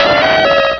pokeemerald / sound / direct_sound_samples / cries / donphan.aif
-Replaced the Gen. 1 to 3 cries with BW2 rips.